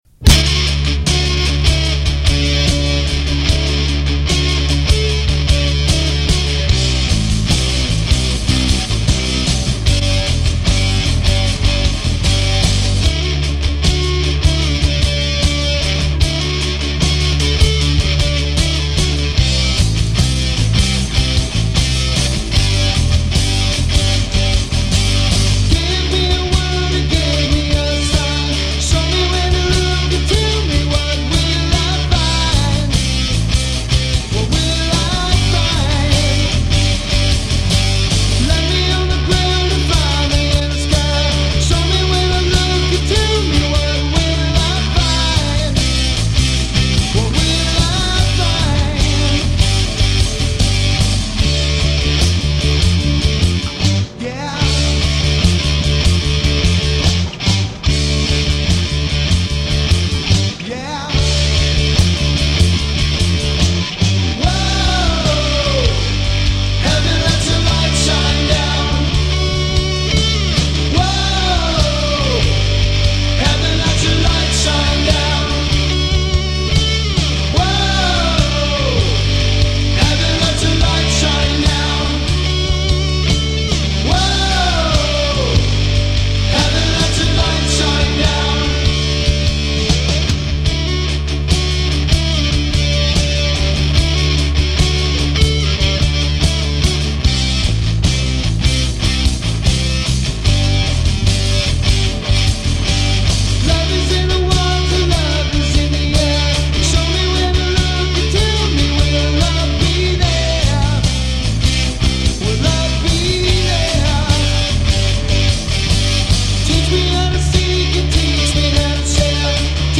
Yea, that’s me on bass… Quality sucks, but what do you expect from an old guy 😛
Recording is copied from a old cassette tape to mp3 Yea, it is that old